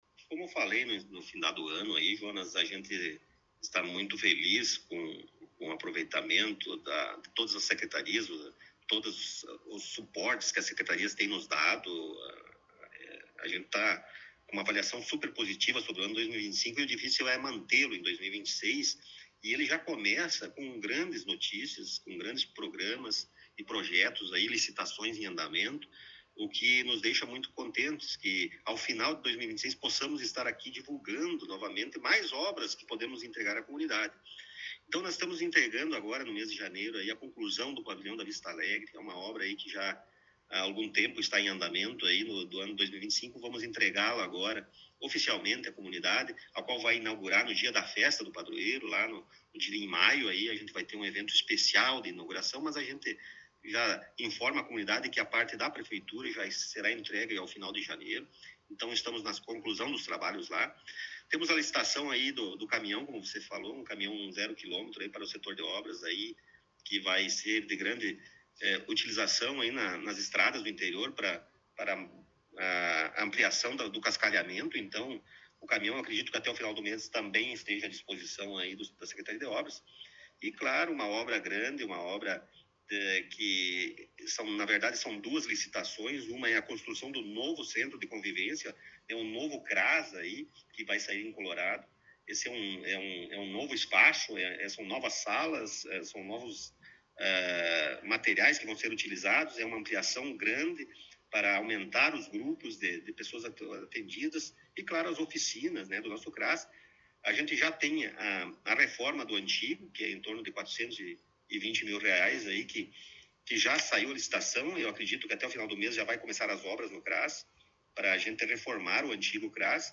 No início deste novo ano, tivemos a oportunidade de entrevistar o prefeito Rodrigo Sartori em seu gabinete na Prefeitura Municipal.